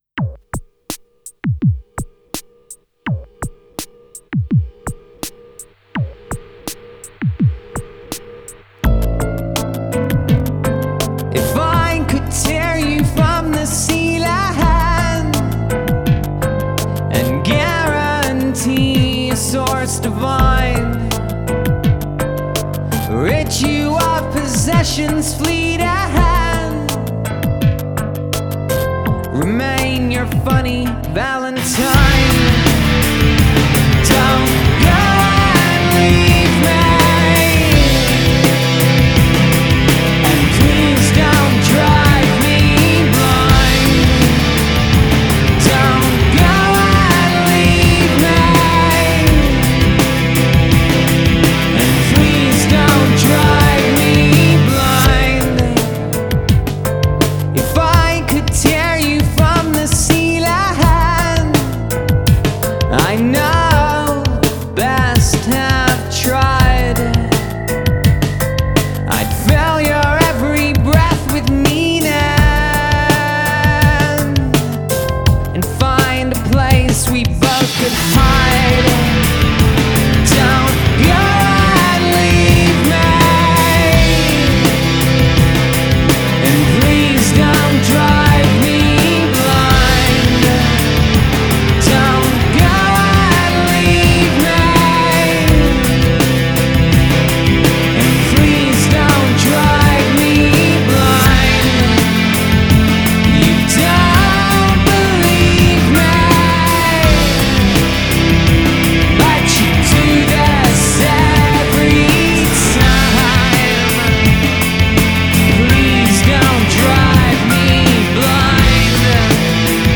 Alternative rock Indie rock Rock